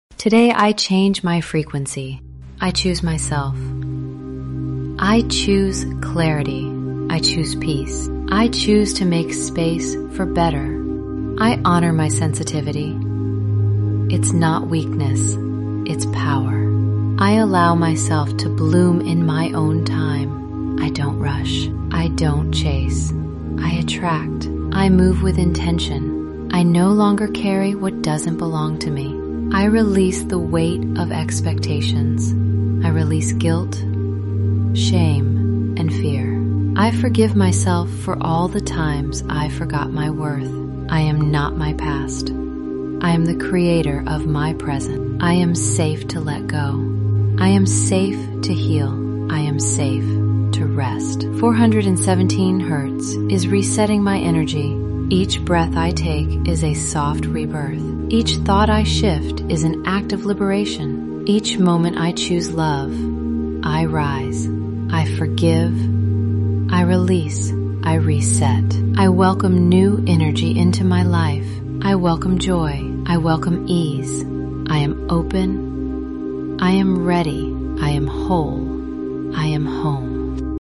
You’re not broken — your energy is realigning. Listen to this 417 Hz reset, and watch how your entire vibration shifts.